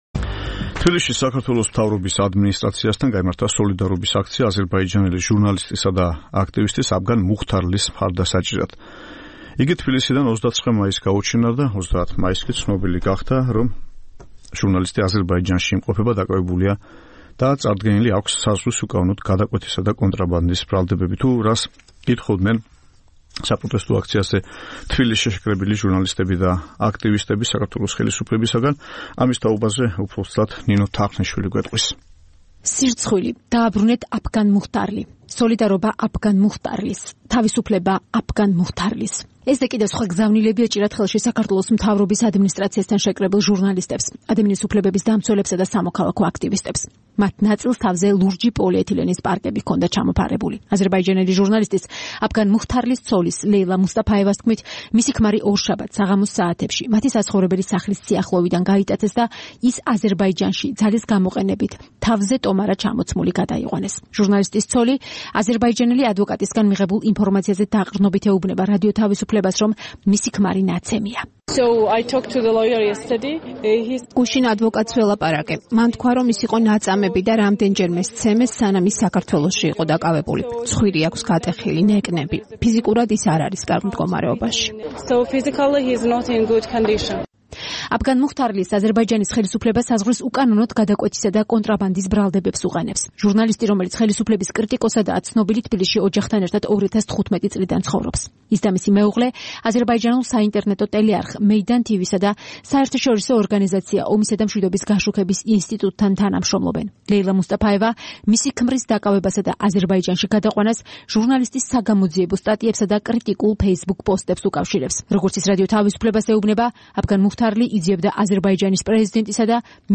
სოლიდარობის აქცია